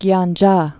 (gyän-jä)